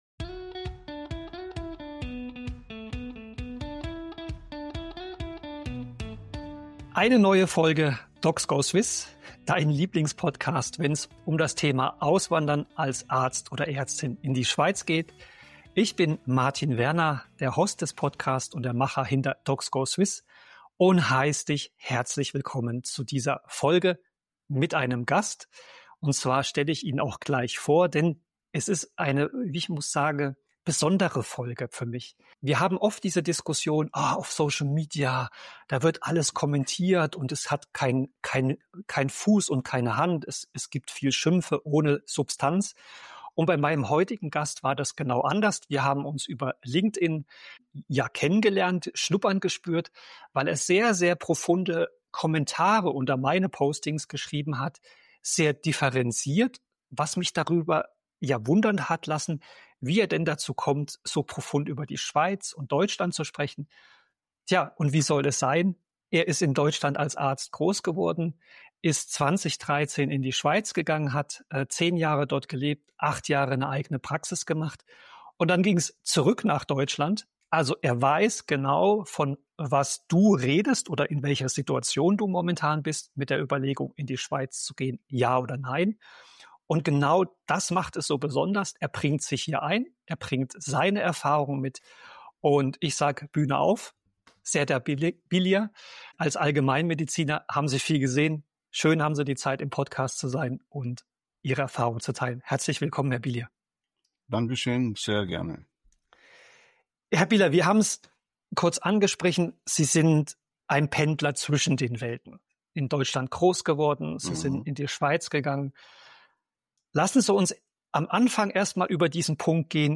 Dann Corona, Post-Covid und die Rückkehr nach Deutschland. Im Gespräch erzählt er ehrlich, was die Schweiz besser macht - und warum er trotzdem zurückging. Plus: Sein größter Fehler beim 3-Säulen-System (Rente).